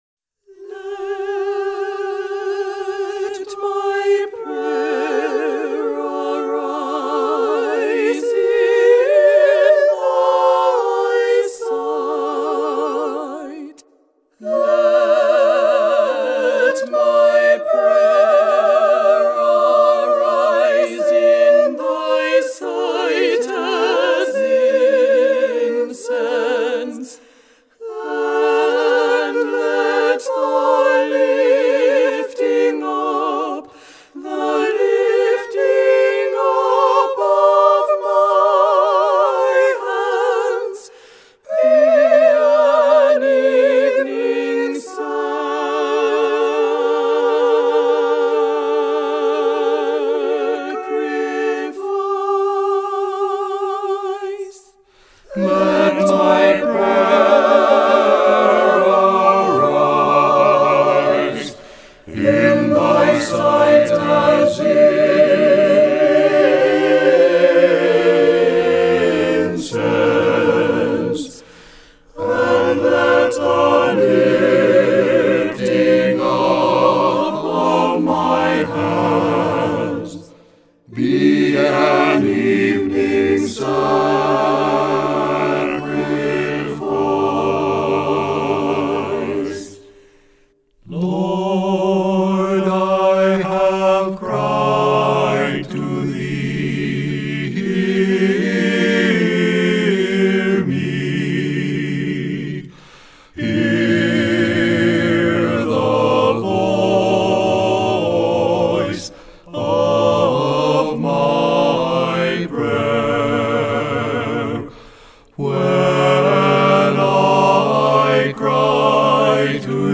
Bortniansky (Trio)
(The trio portion of this is the same as ours, but the refrain is very different. Please disregard refrain.)